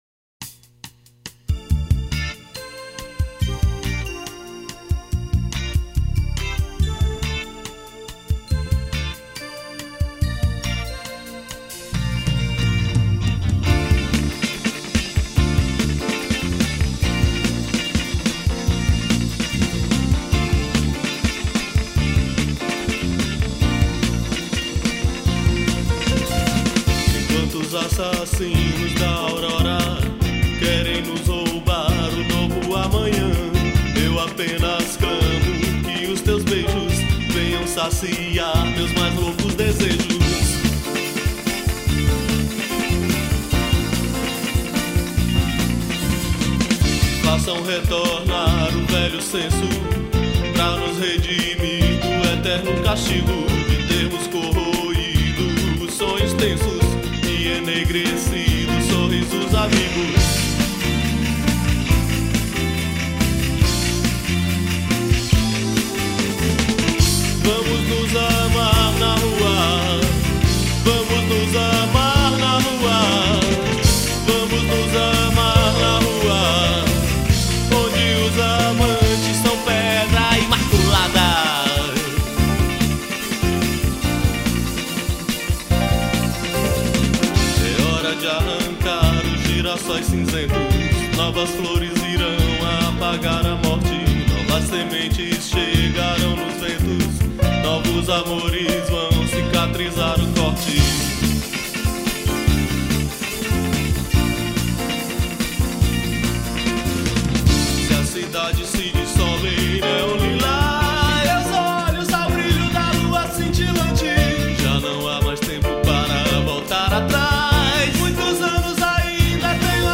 1035   03:04:00   Faixa:     Rock Nacional
Teclados
Baixo Elétrico 6